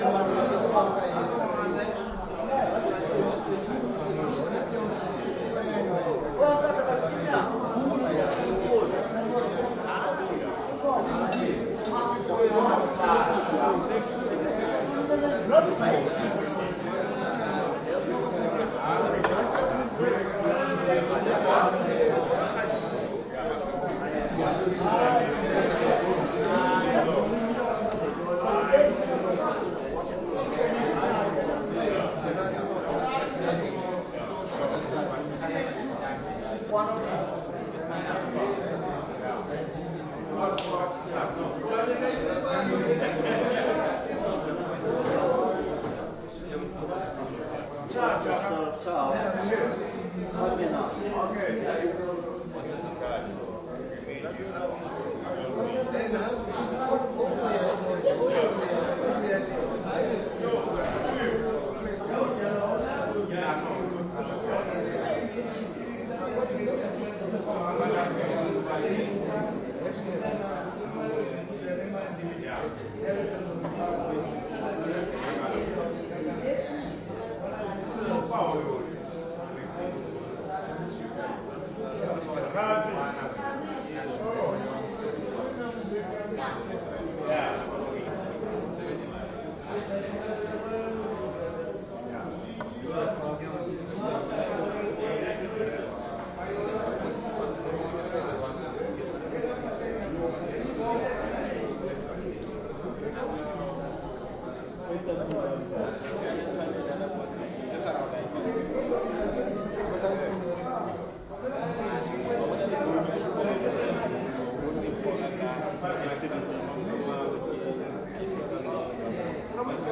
Peru » street corner side street quiet night distant traffic and people and barking dog echo Cusco, Peru, South America
标签： South America corner ambience night Peru traffic street dog distant quiet ambient people
声道立体声